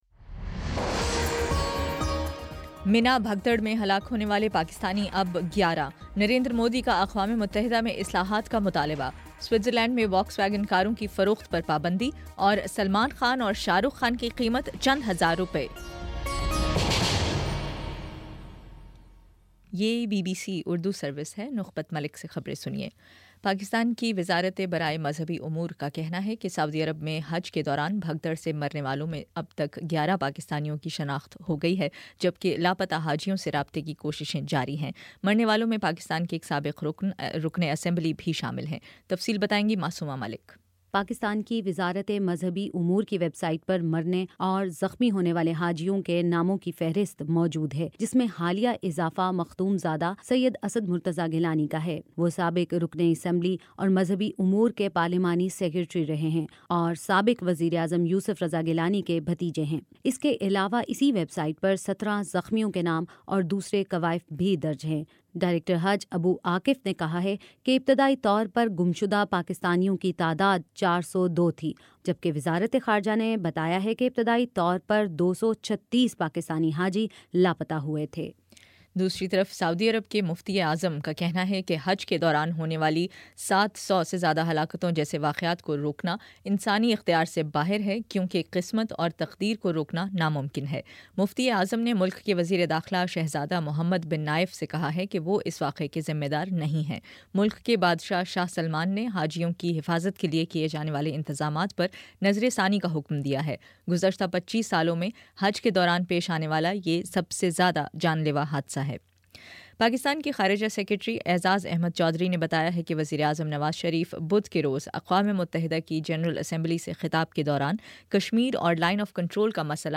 ستمبر26 : شام چھ بجے کا نیوز بُلیٹن